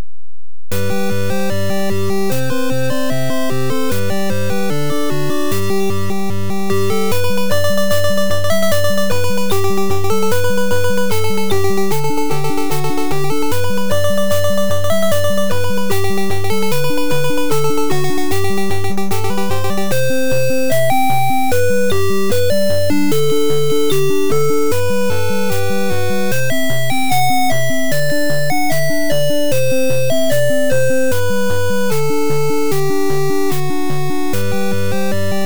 level 2's music looped for the twentieth time or so...